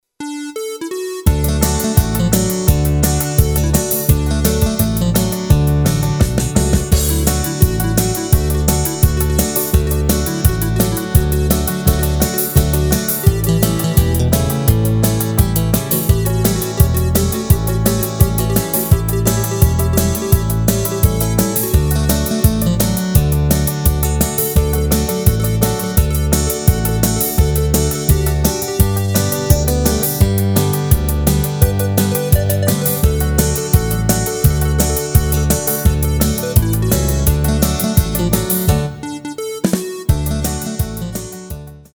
Rubrika: Folk, Country
HUDEBNÍ PODKLADY V AUDIO A VIDEO SOUBORECH